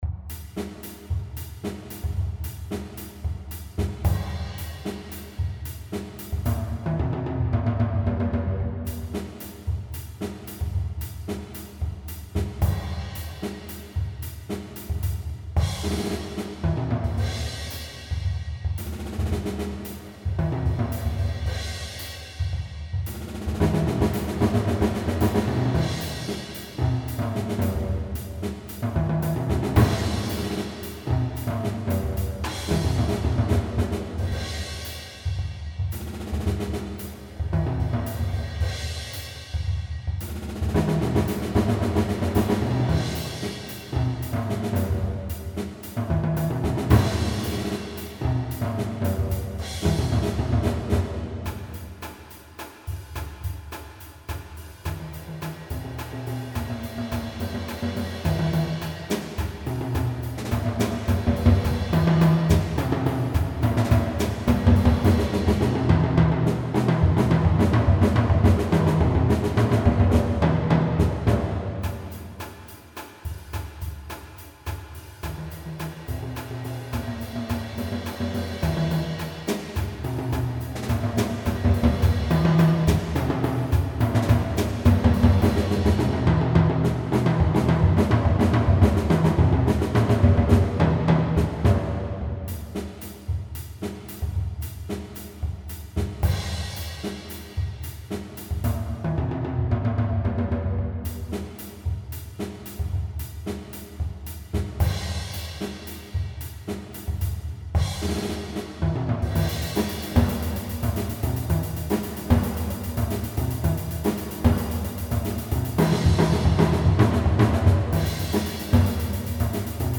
Genre Entertainment
Bassdrum | Cymbal Susp. | Hihat | Snaredrum | Toms